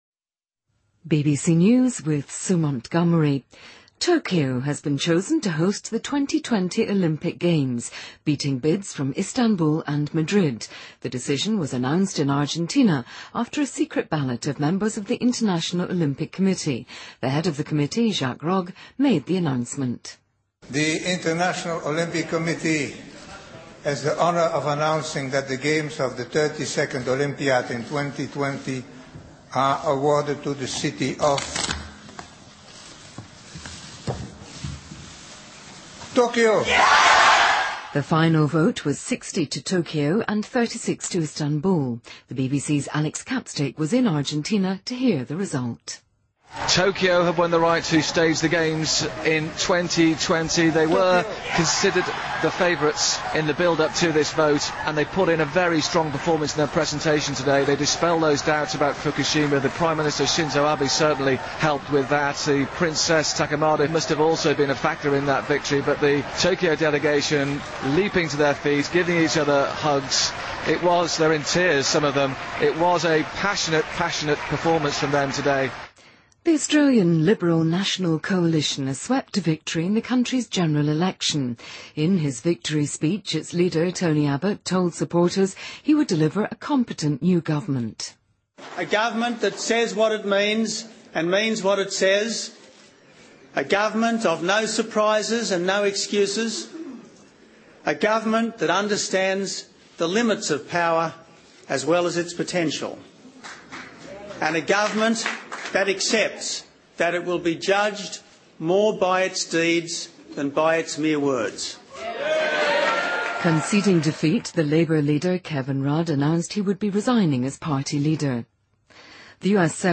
BBC news,东京获得2020年奥运会的举办权